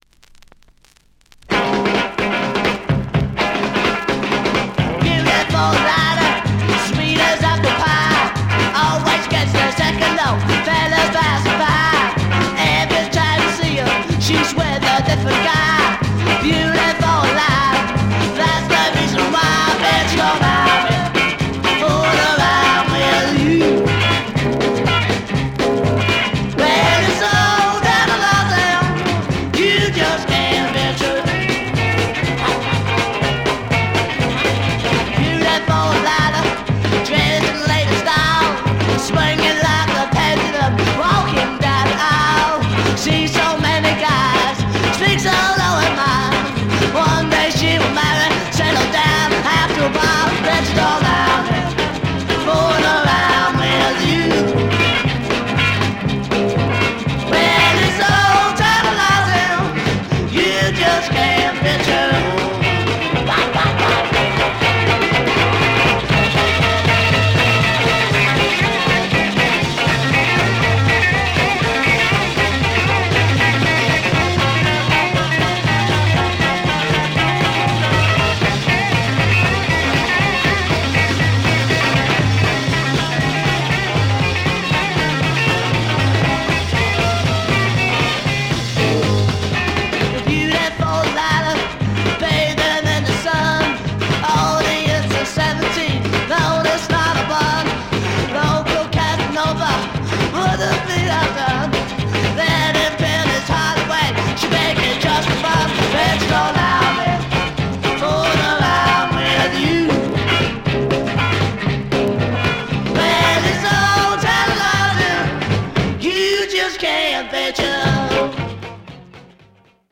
Classic UK Freakbeat garage, rare French EP issue.